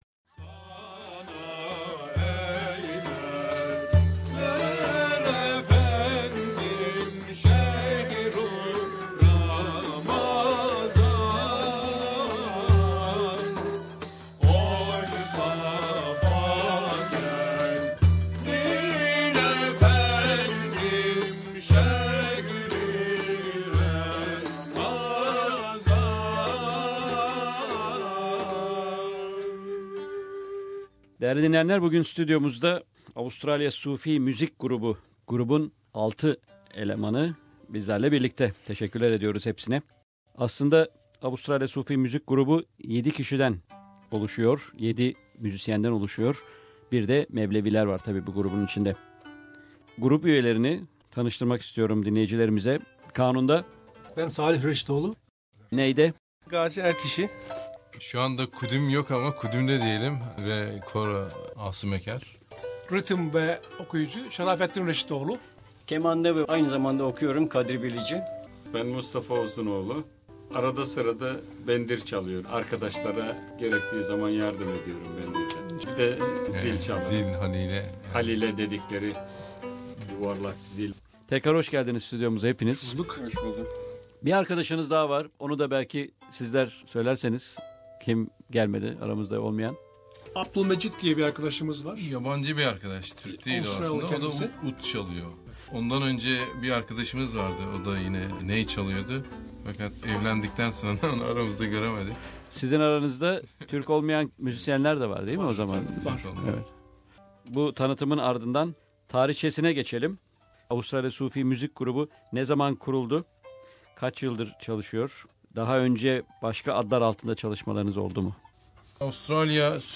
SBS Türkçe programı, SBS'i ziyaret eden Avustralya Sufi Müzik Grubunun üyeleri ile söyleşi gerçekleştirirken Grup da Sufi müzikten örnekler sundu.